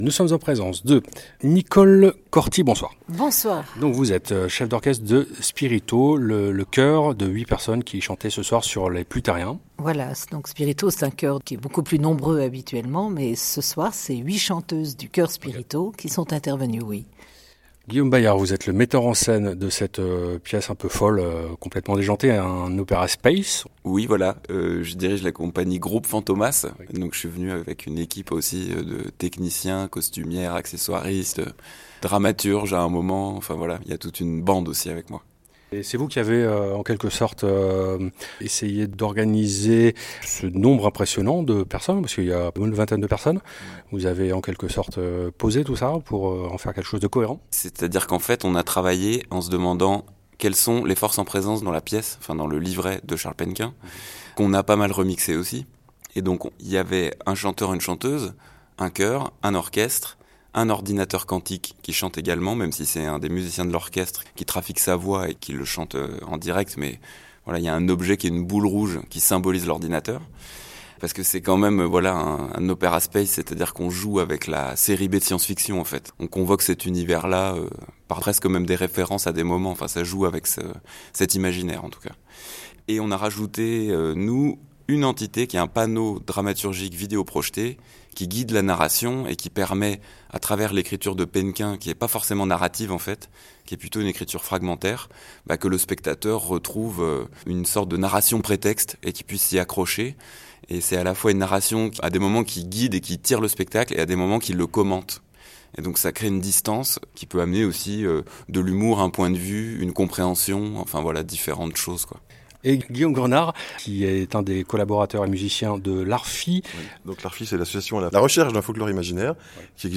En bonus, un entretien téléphonique avec Ol